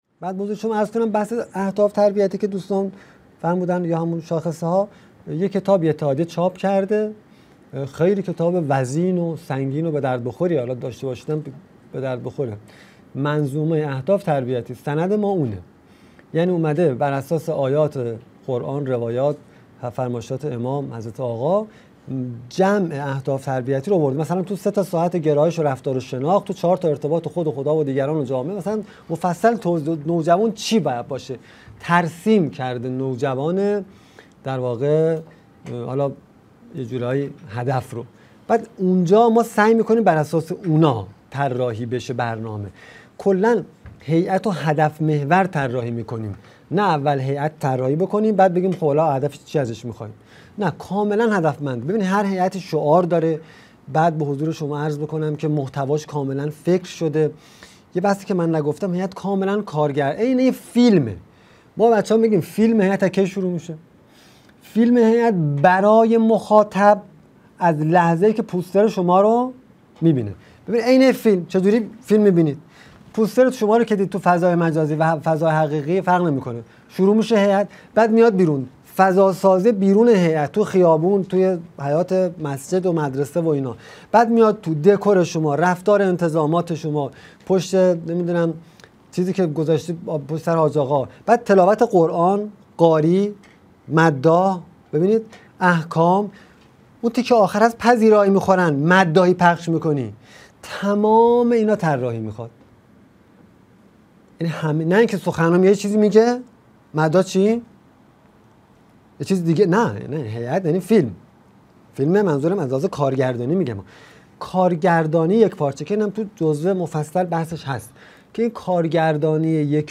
مدرسه عالی هیأت | گزیده سیزدهم از سومین سلسله نشست‌ های هیأت و نوجوانان